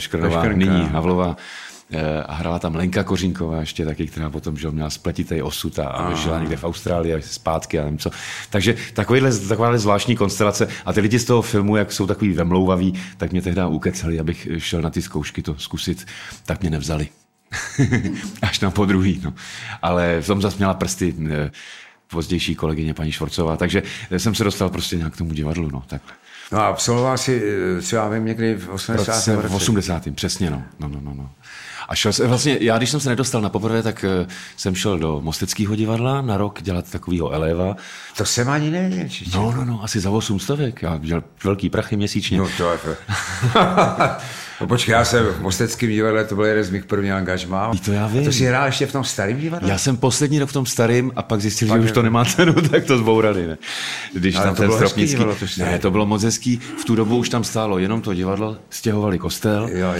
Audiobook
Read: Radoslav Brzobohatý